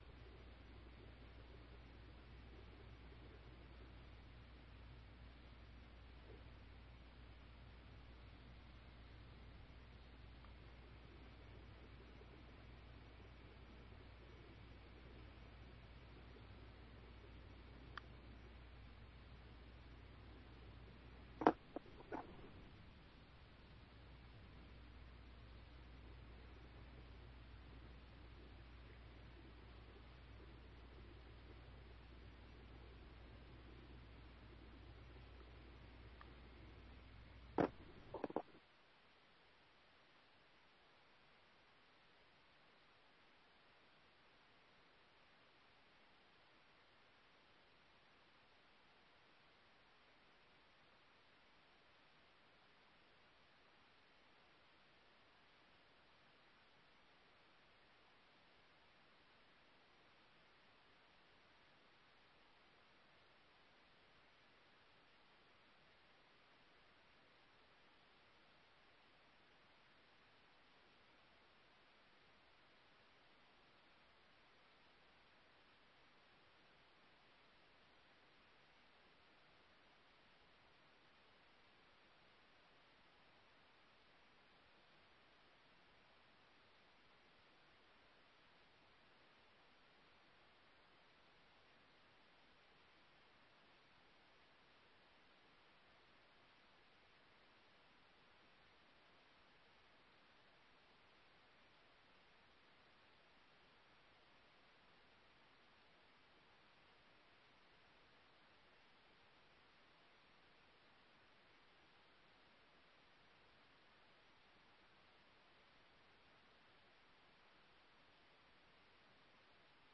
You can listen to the Tele-Town Hall here.